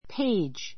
péidʒ